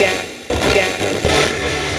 120BPMRAD3-L.wav